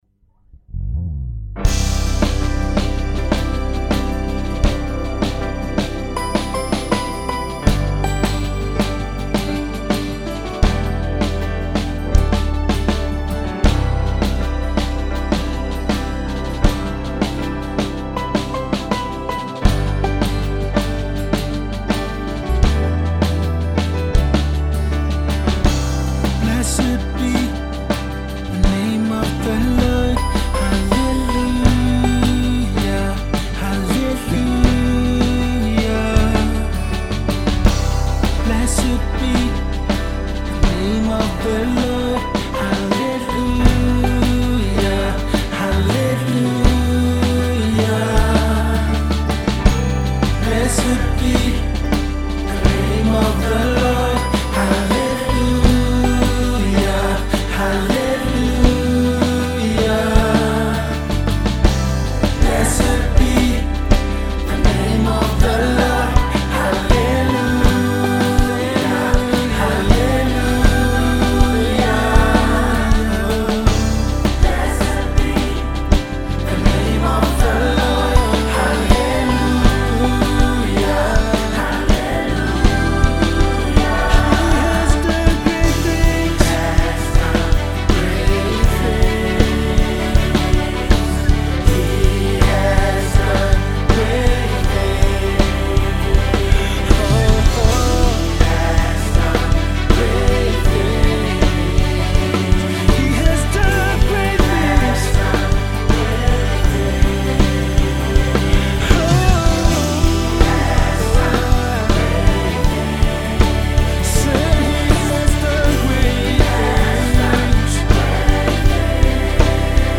Multiple award-winning gospel music artiste